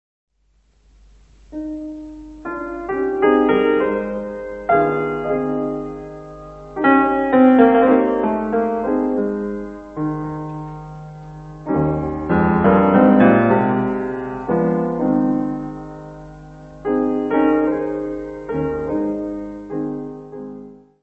piano.